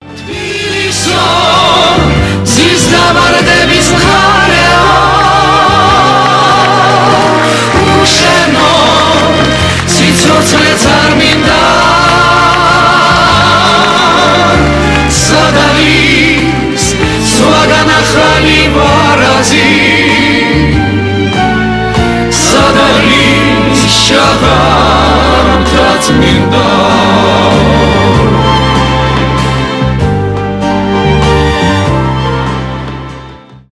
эстрадные
поп
live